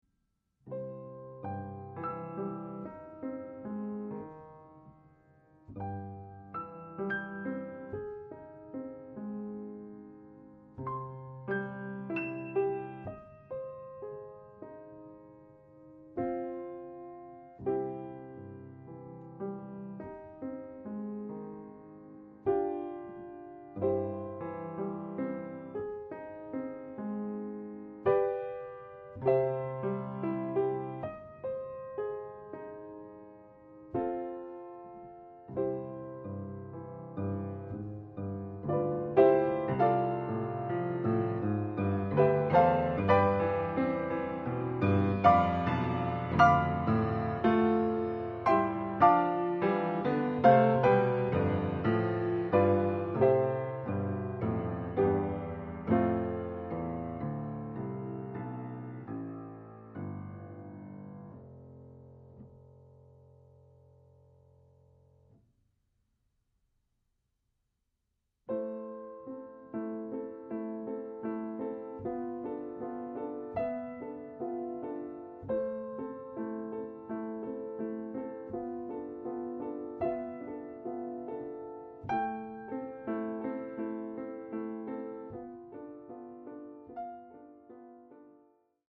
in do